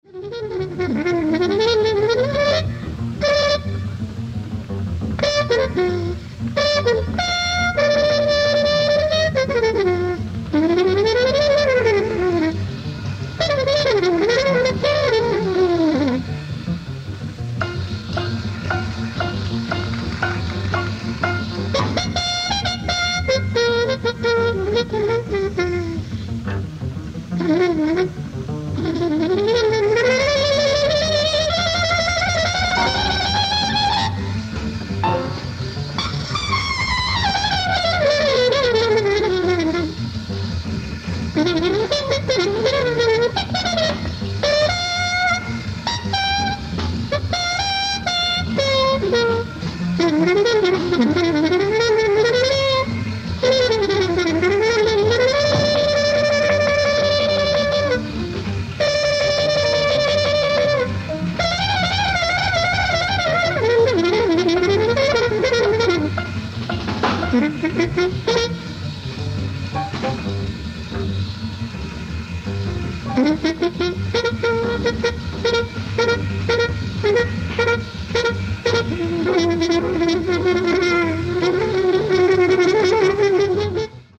ライブ・アット・円山公演野外音楽堂、京都 07/15/1964
※試聴用に実際より音質を落としています。